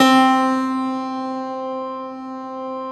53e-pno10-C2.wav